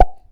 pluck.wav